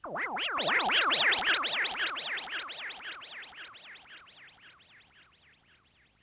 Microwave 2.wav